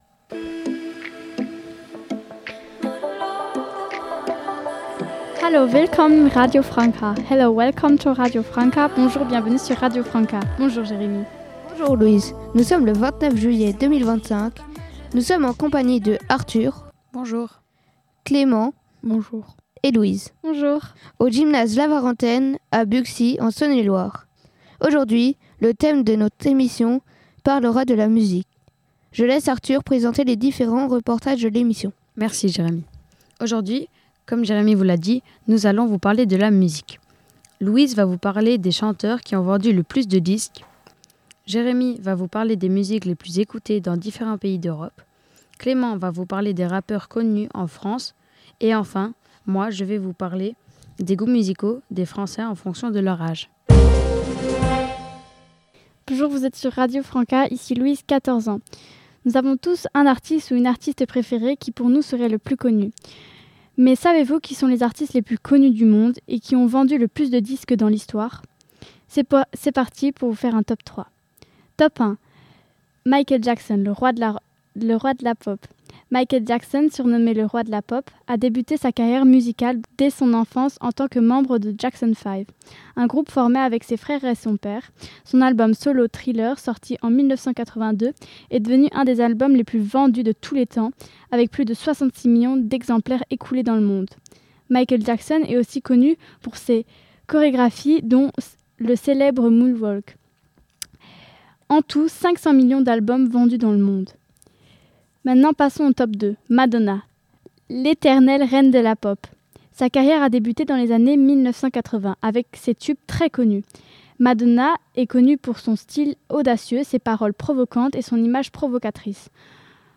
Emission radio sur la musique, réalisée par des ados de Buxy